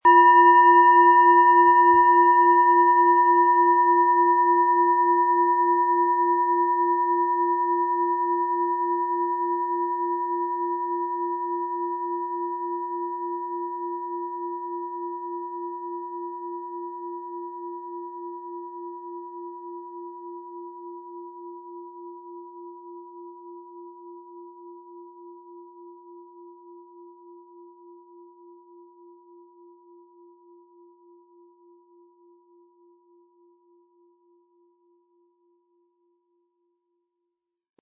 Platonisches Jahr
• Einsatzbereich: Über dem Kopf sehr intensiv spürbar. Ein unpersönlicher Ton.
• Mittlerer Ton: Lilith
Im Sound-Player - Jetzt reinhören können Sie den Original-Ton genau dieser Schale anhören.
SchalenformOrissa
MaterialBronze